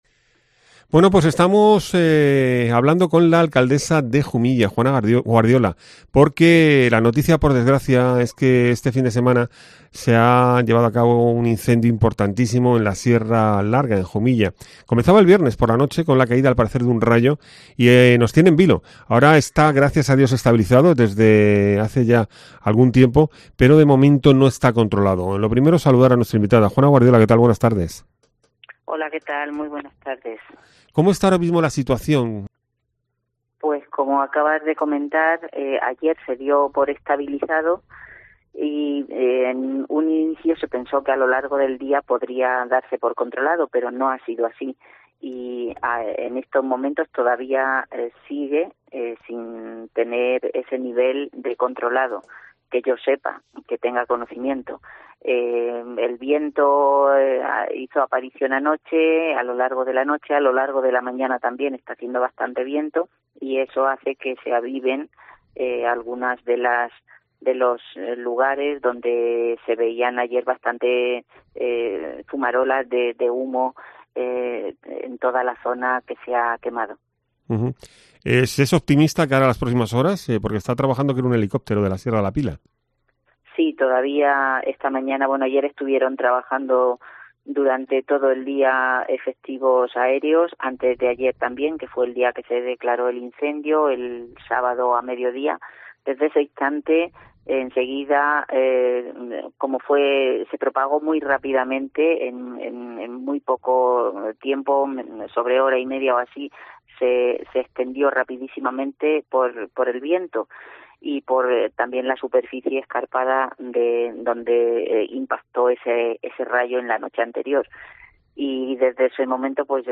ENTREVISTA
Juana Guardiola, alcaldesa de Jumilla